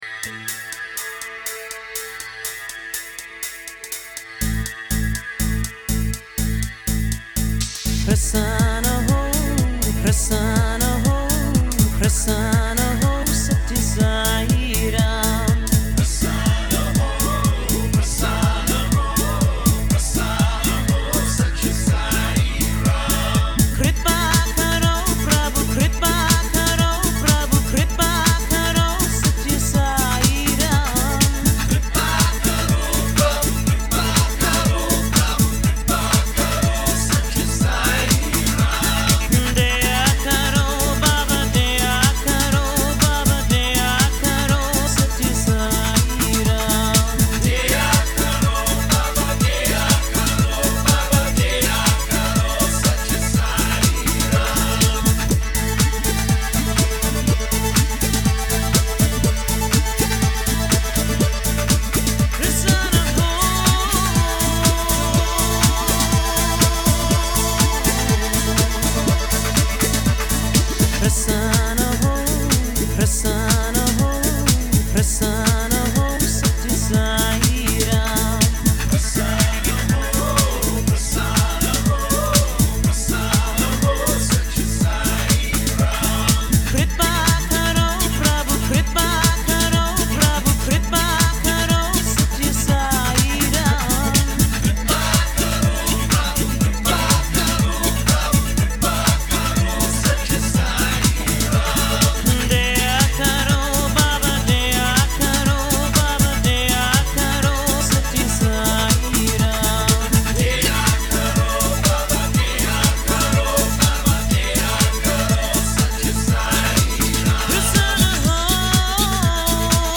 Индийские бажданы в шедевральной аранжировке.
Стиль: Ethnic New Age UK / USA